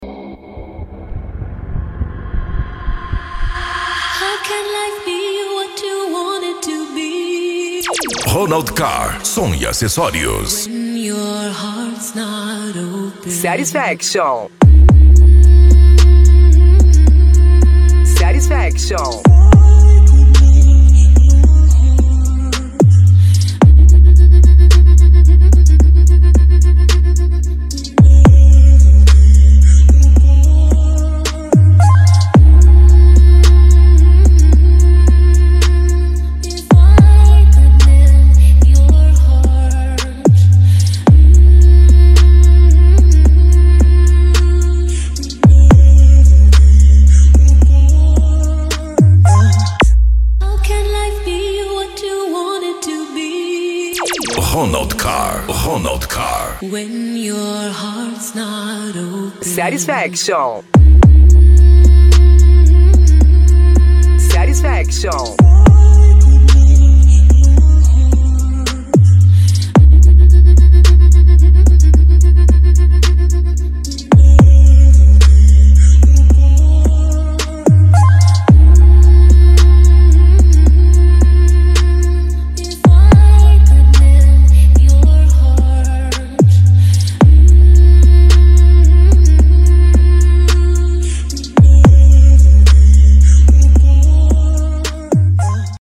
Funk
Musica Electronica